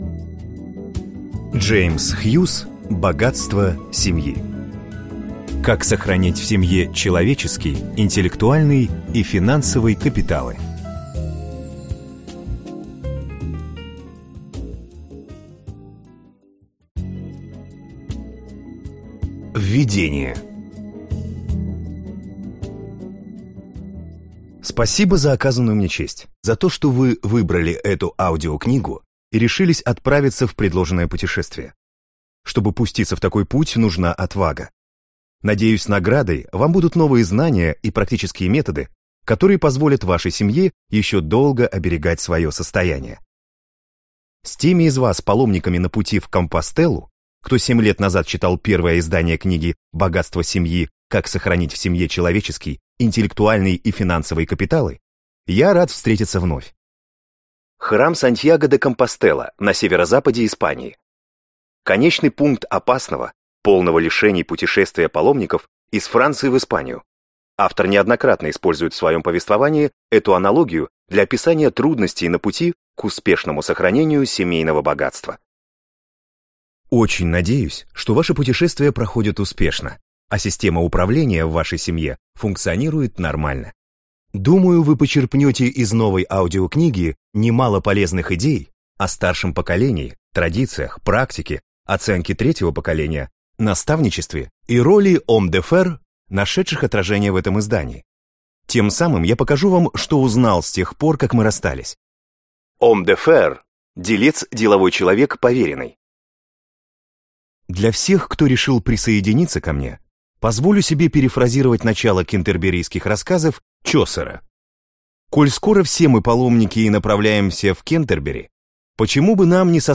Аудиокнига Богатство семьи. Как сохранить в семье человеческий, интеллектуальный и финансовый капиталы | Библиотека аудиокниг